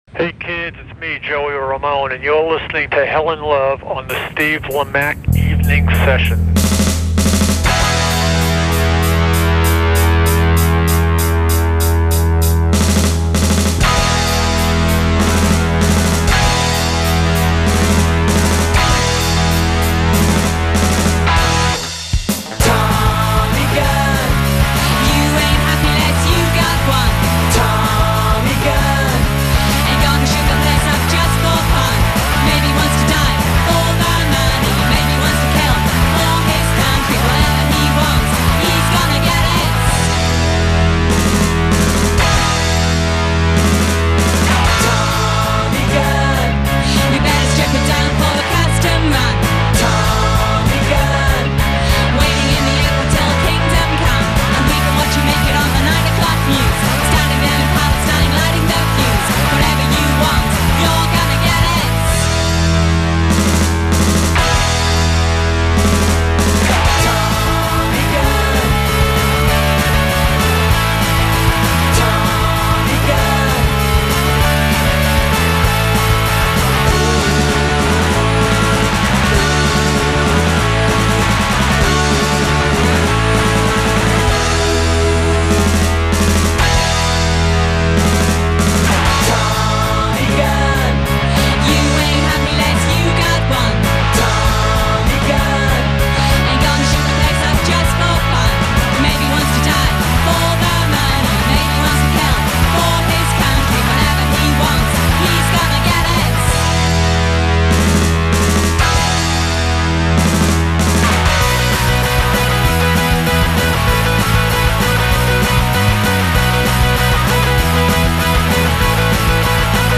A veritable turbo-soup of Punk and bubblegum.